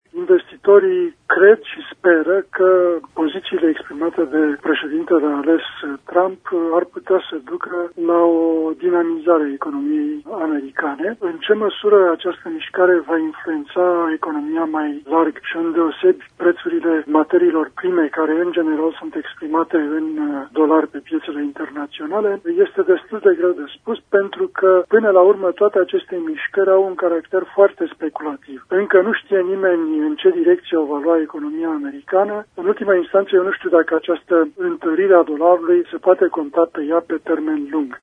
Analistul economic